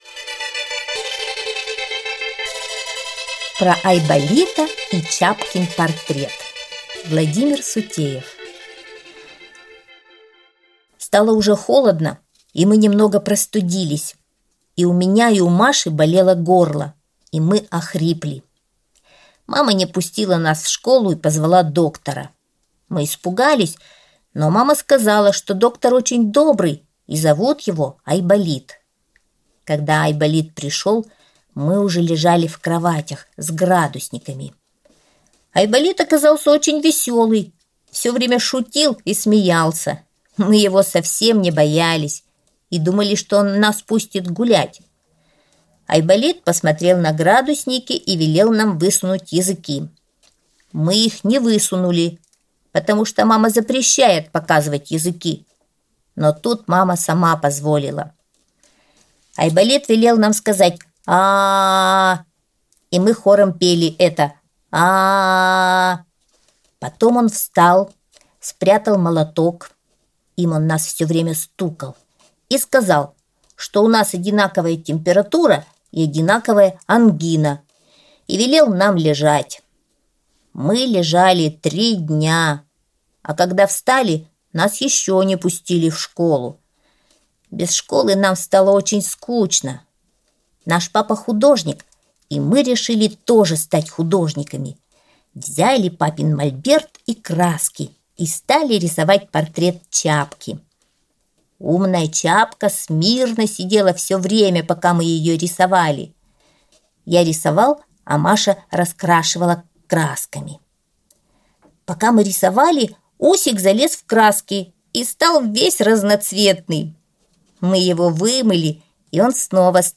Аудиосказка «Про Айболита и Чапкин портрет»